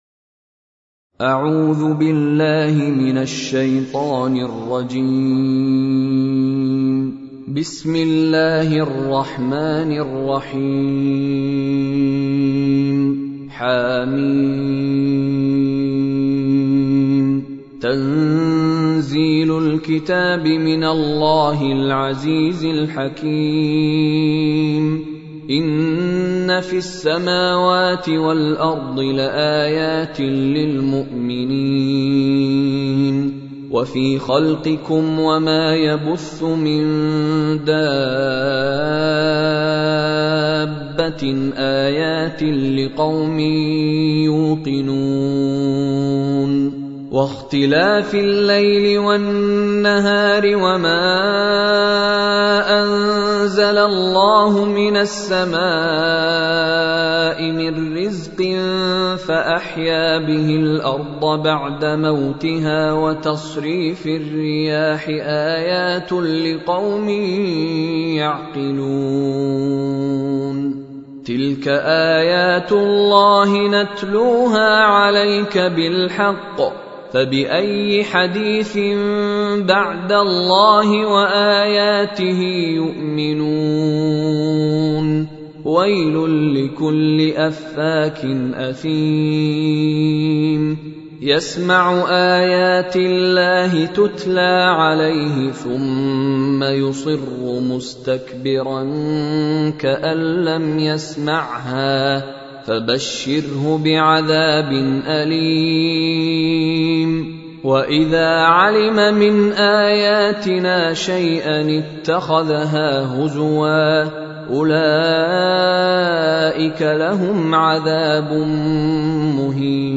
مشغل التلاوة
مشغل التلاوة استمع للتلاوة العطرة القارئ الحالي Mishary Alafasi العفاسي متصفحك لا يدعم تشغيل الصوت.